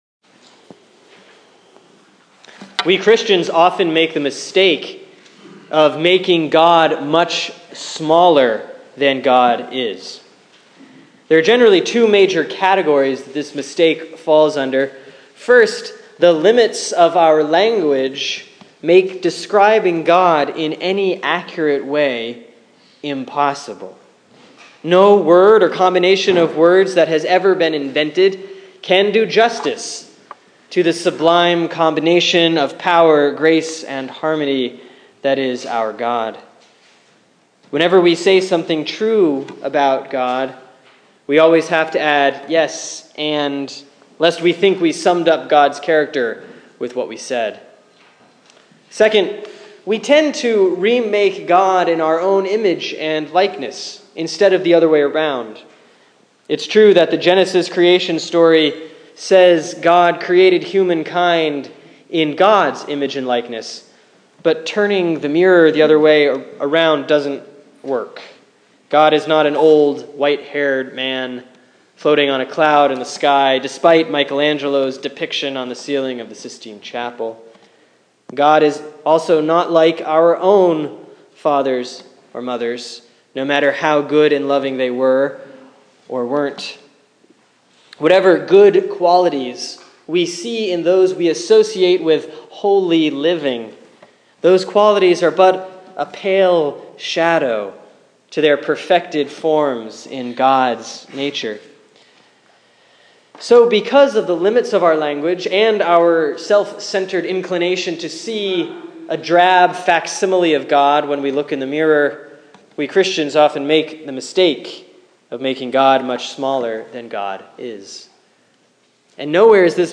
Sermon for Sunday, March 15, 2015 || Lent 4B || John 3:14-21